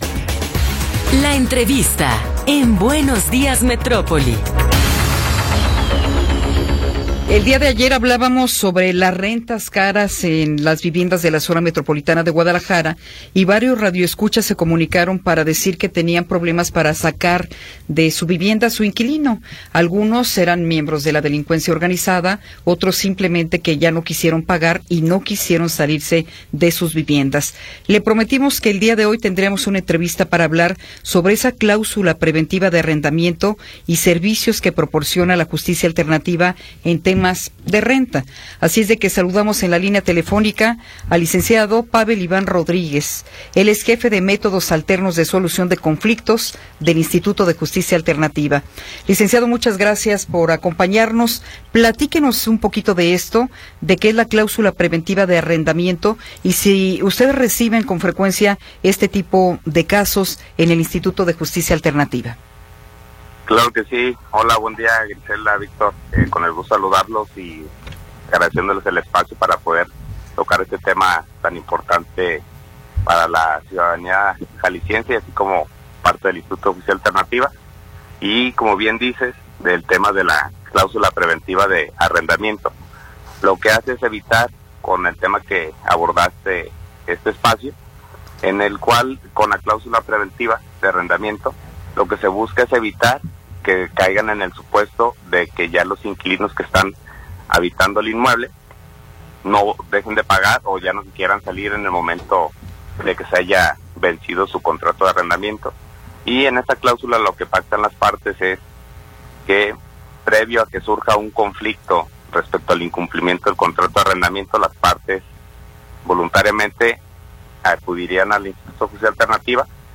Entrevista
entrevista.m4a